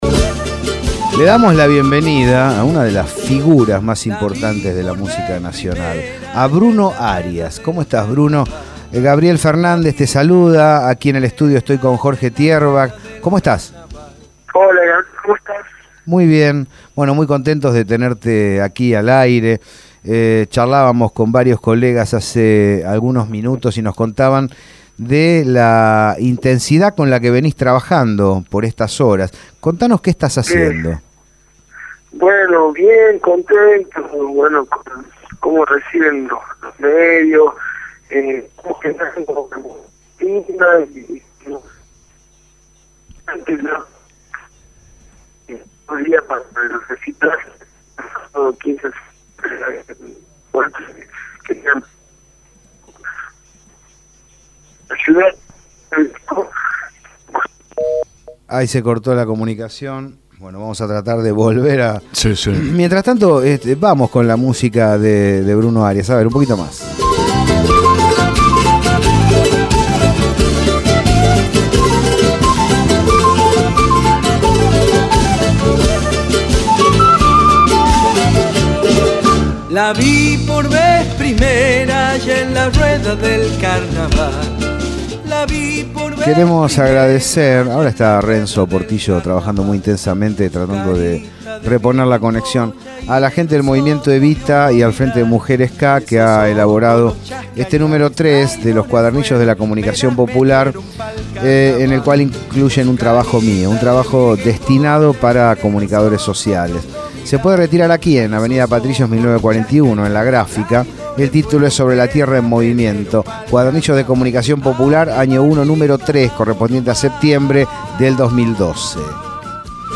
En conversación con